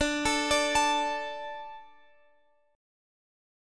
D 5th